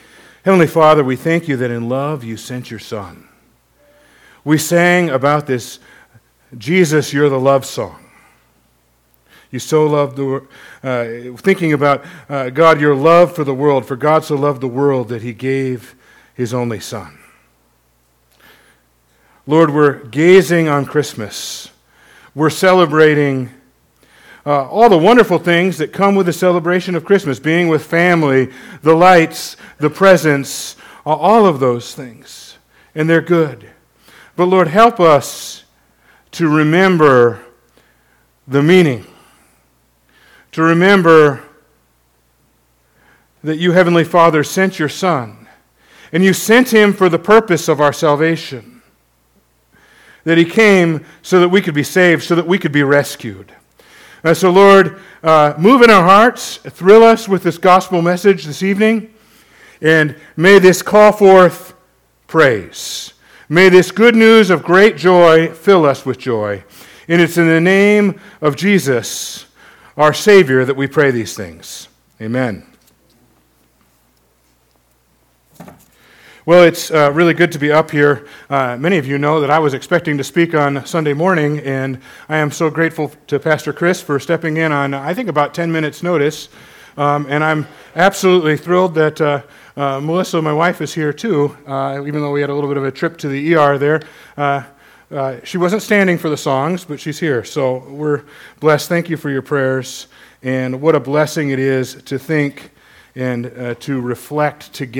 December 24, 2025 - Christmas Eve - Arthur Evangelical Free Church